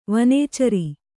♪ vanēcari